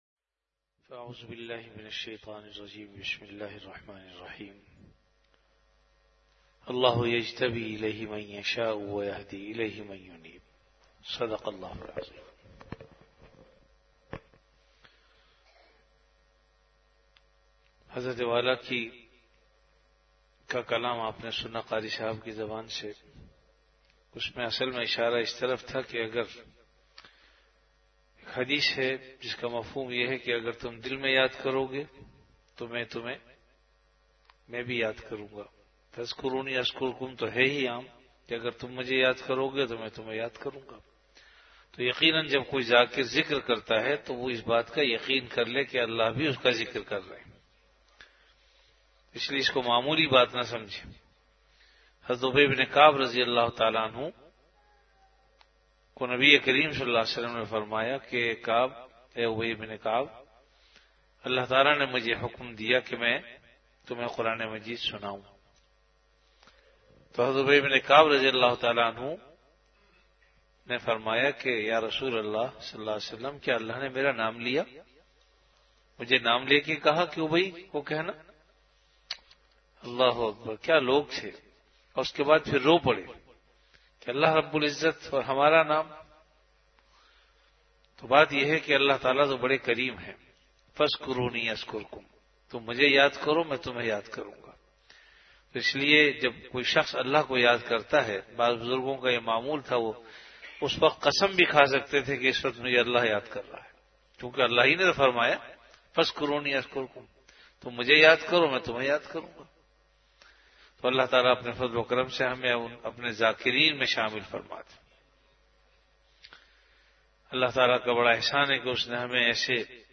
An Islamic audio bayan